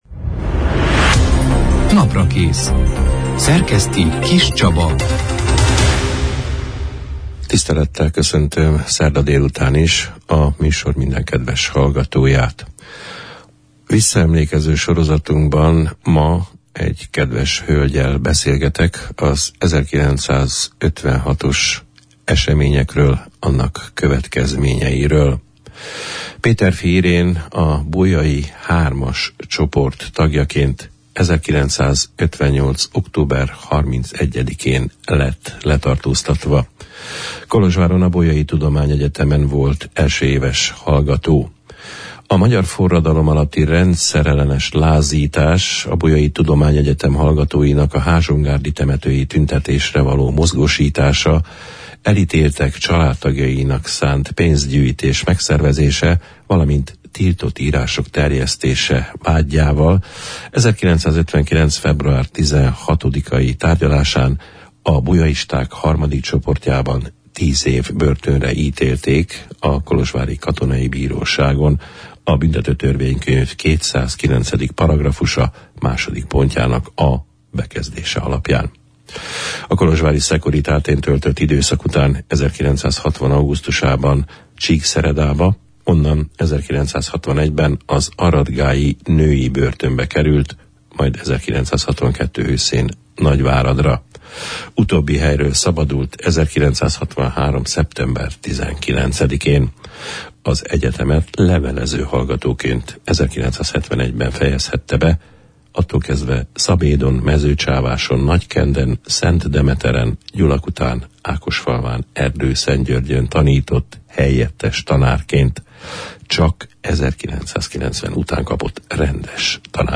Egy elsőéves egyetemi hallgató megpróbáltatásairól, életpályájának derékba töréséről, börtönéveiről, karrierje alakulásáról , szabadulása után, emlékeinek megható őrzéséről, a megbocsátásról beszélgettünk az október 19 -én, szerdán elhangzott Naprakész műsorban